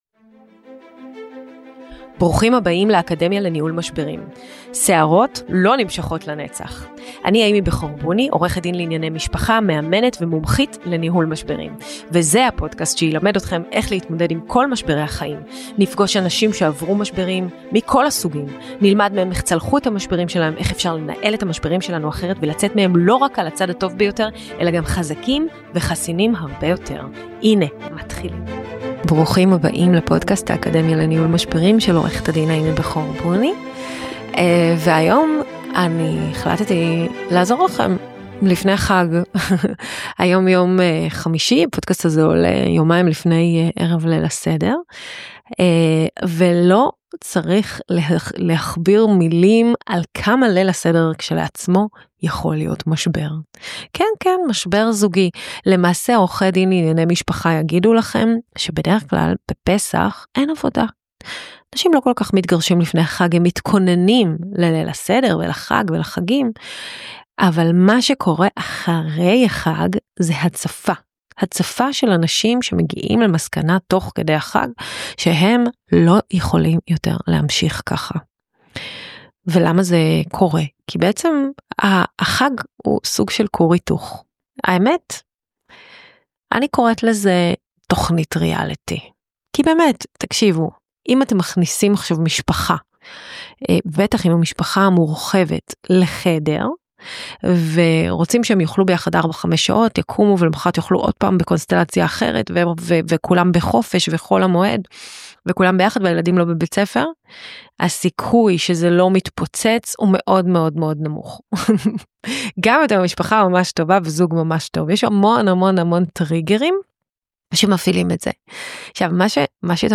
הפרק ה 131 של ״האקדמיה לניהול משברים״, גם הוא פרק סולו מיוחד והפעם בגלל הפסח.